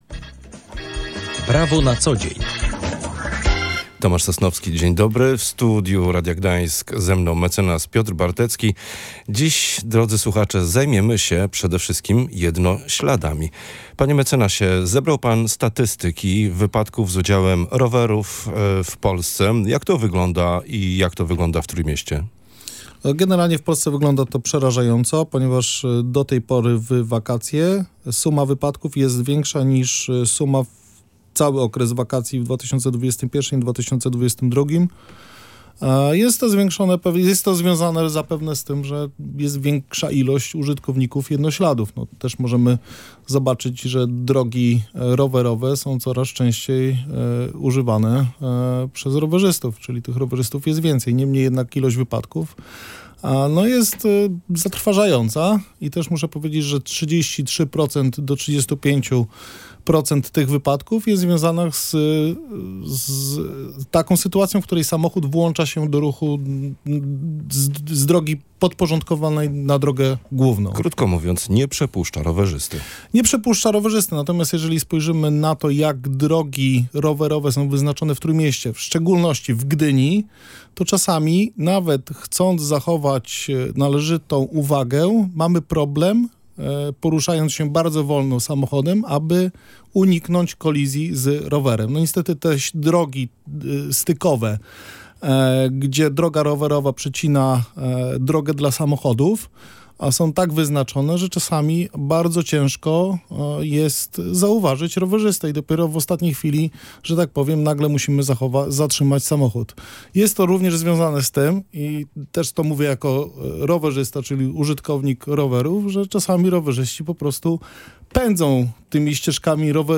Prawnik o rosnącej liczbie wypadków z udziałem rowerzystów